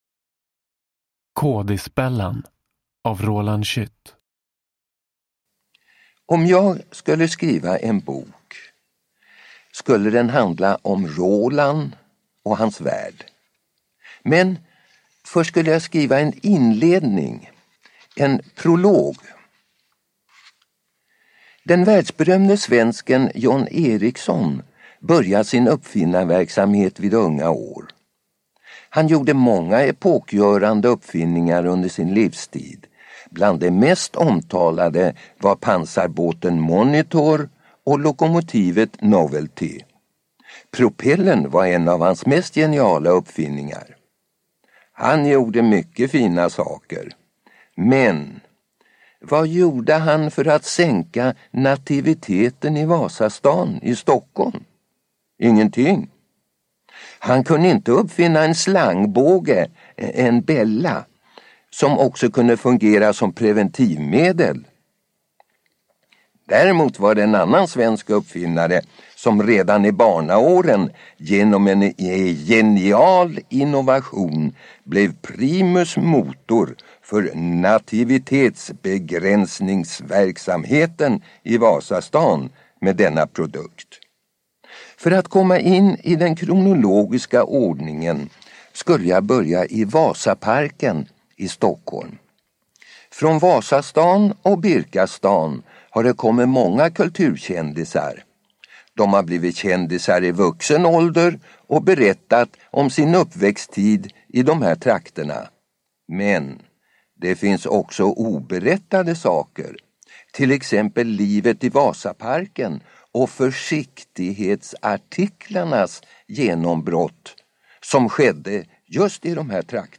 Kådisbellan – Ljudbok – Laddas ner
Kådisbellan är en humoristisk och varm berättelse om en judisk pojkes tuffa uppväxt i 20-talets Stockholm. Originalinspelningen gjordes 1990.